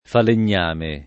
falegname [ falen’n’ # me ] s. m.